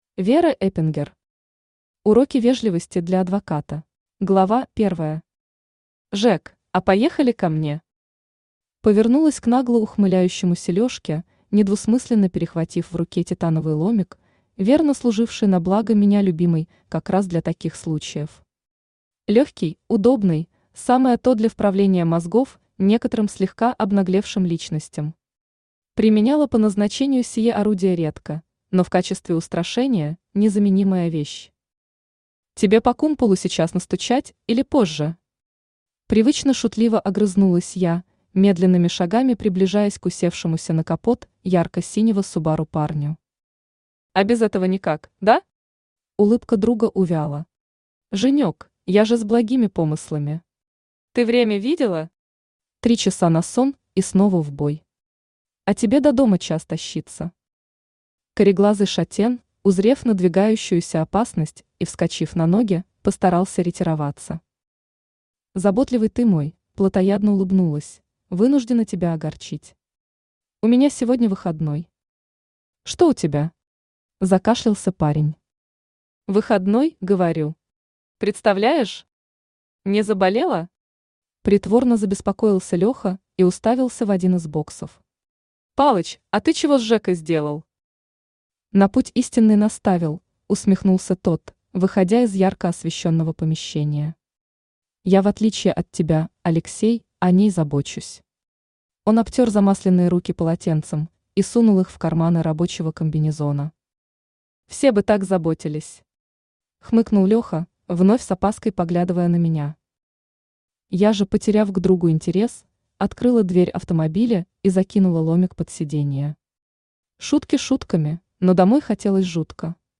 Аудиокнига Уроки вежливости для адвоката | Библиотека аудиокниг
Aудиокнига Уроки вежливости для адвоката Автор Вера Эпингер Читает аудиокнигу Авточтец ЛитРес.